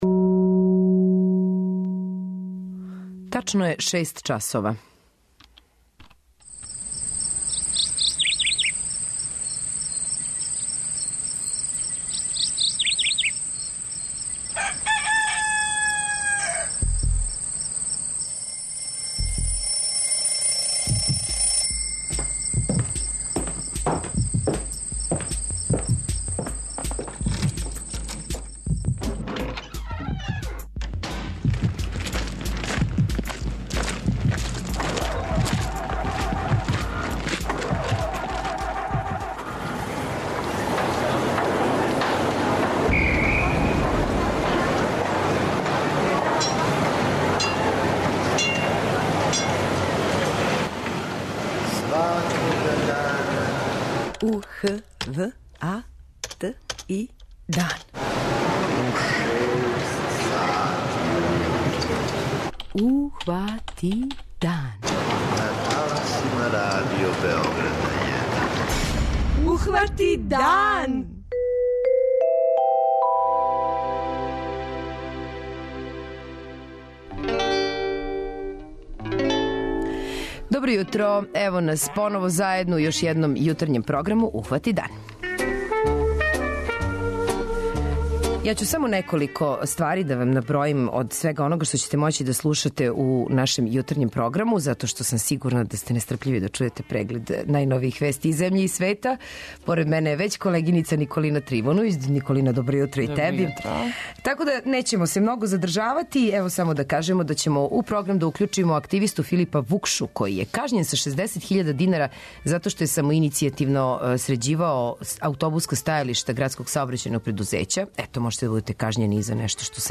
Данас је Светски дан борбе против дијабетеса. Како изгледа свакодневни живот са овом болешћу чућемо од активиста удружења дијабетичара Плави круг који ће бити гости у нашем студију.